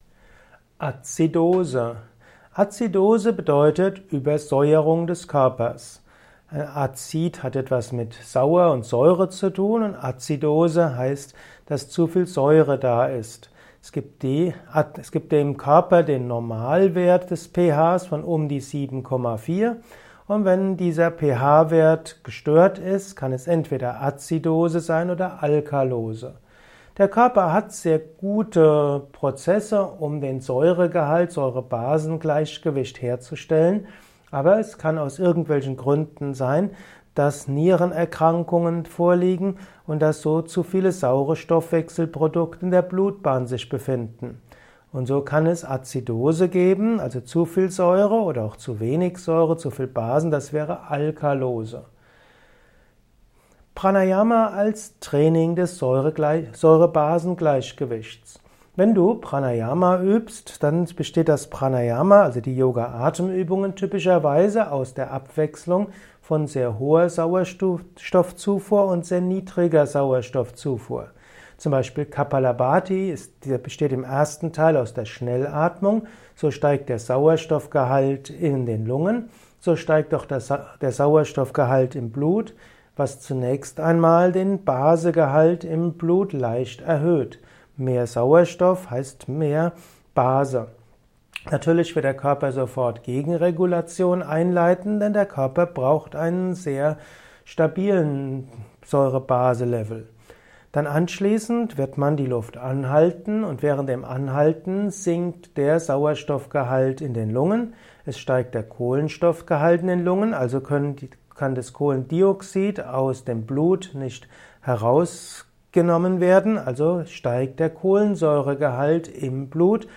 Kompakte Informationen zu dem Begriff Azidose in diesem Kurzvortrag